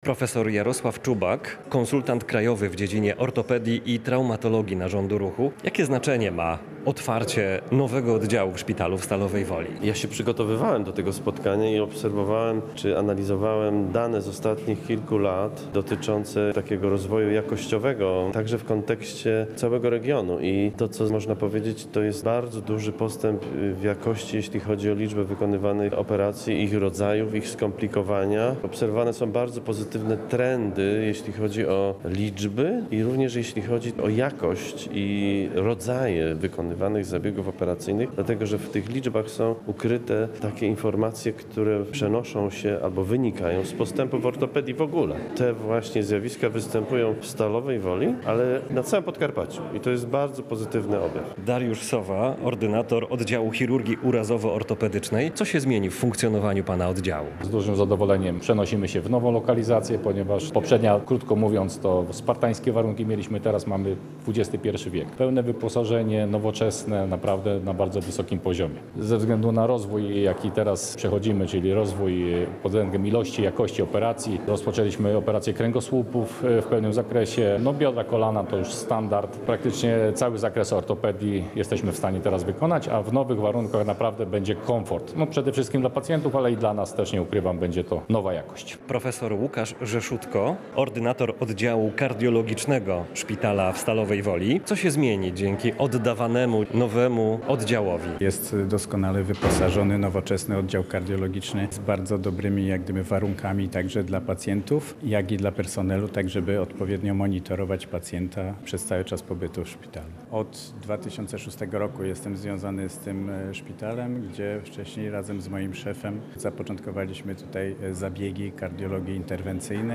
Całość inwestycji kosztowała prawie 10 milionów złotych. Relacja